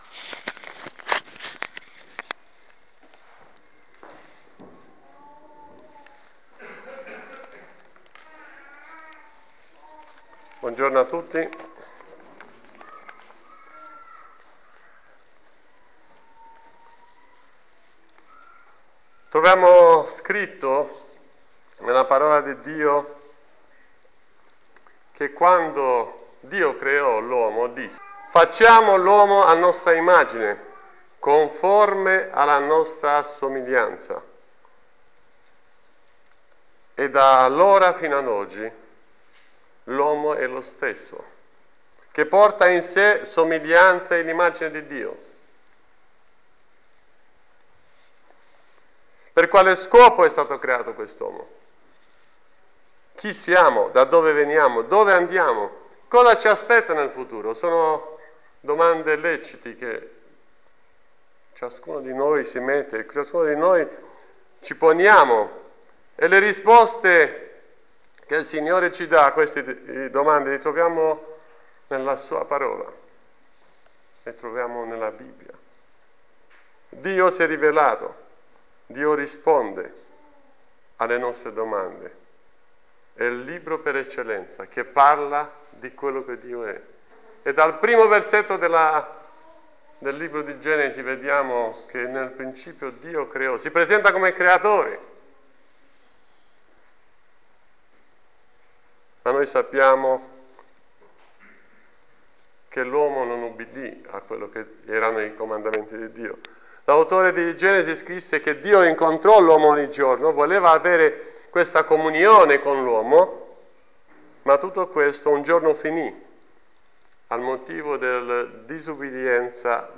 Insegnamenti dal passo di Giovanni 3:16-18